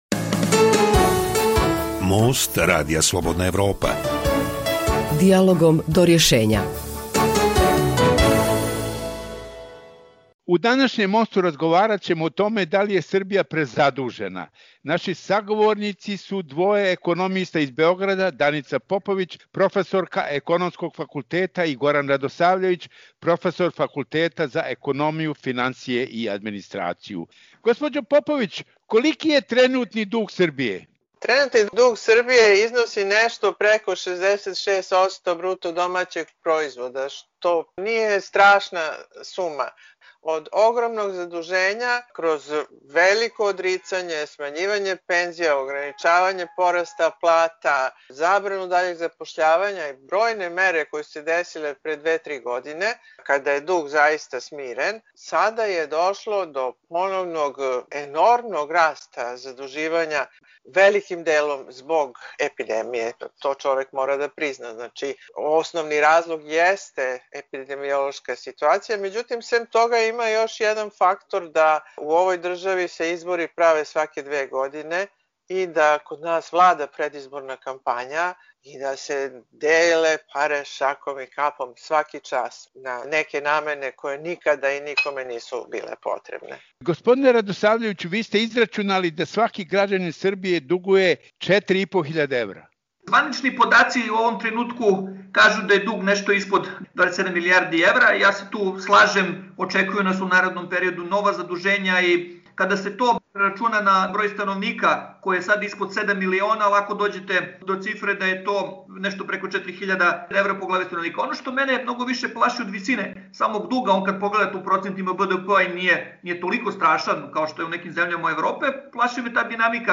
Tema najnovijeg Mosta Radija Slobodna Evropa bila je da li je Srbija prezadužena. Sagovornici su bili dvoje ekonomista iz Beograda